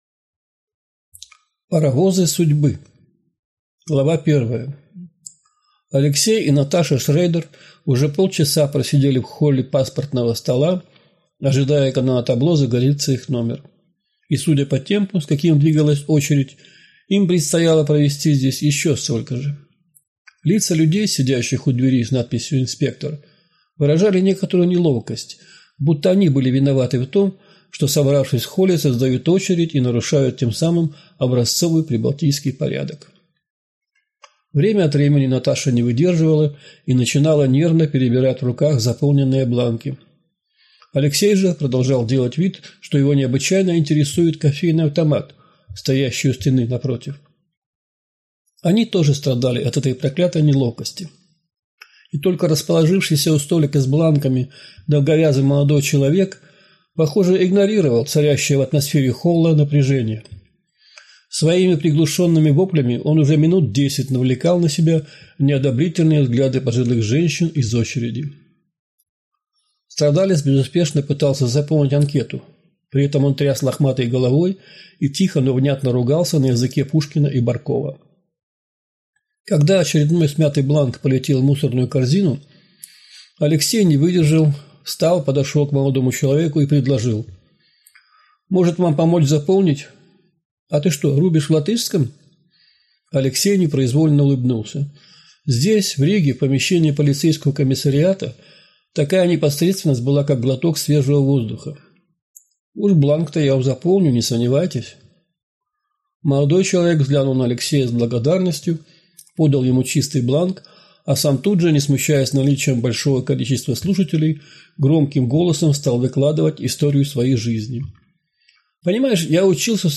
Аудиокнига Паровозы судьбы | Библиотека аудиокниг